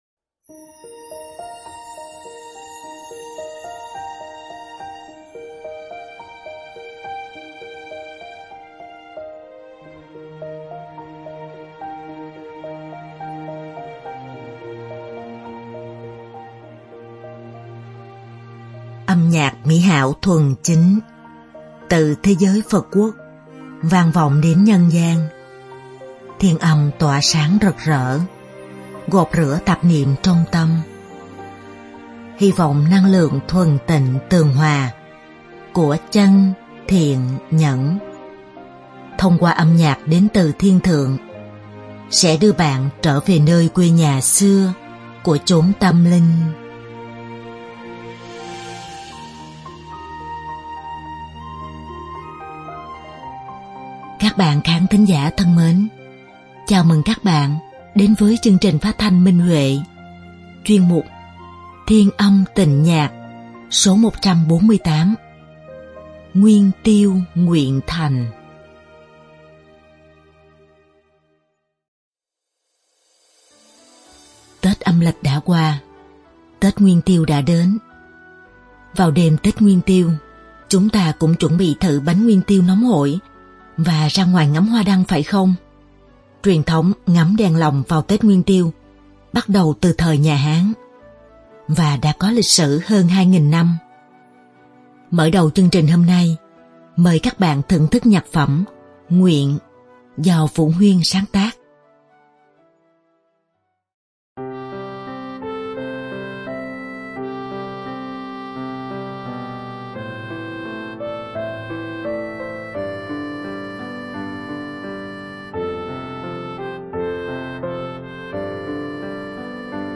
Ca khúc thiếu nhi
Đơn ca nữ